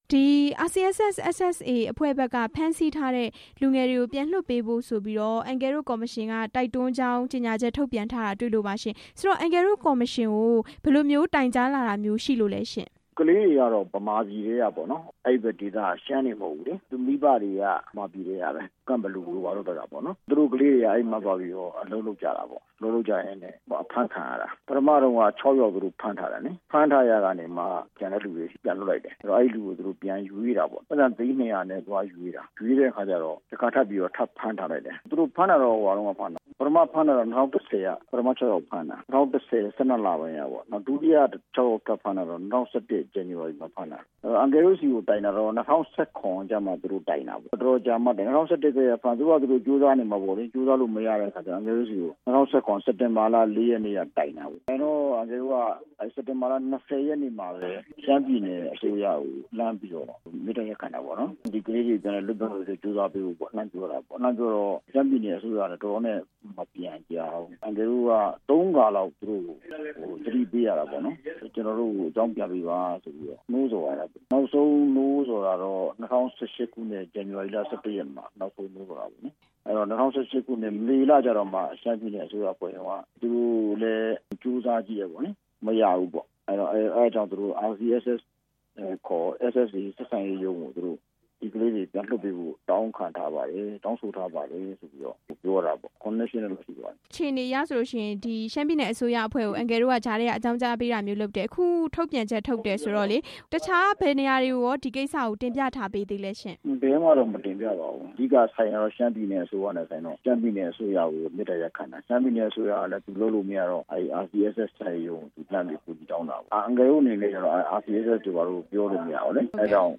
RCSS/SSA က ဖမ်းထားတဲ့ အလုပ်သမား ၁၂ ဦးအကြောင်း ဆက်သွယ်မေးမြန်းချက်
မေးမြန်းခန်း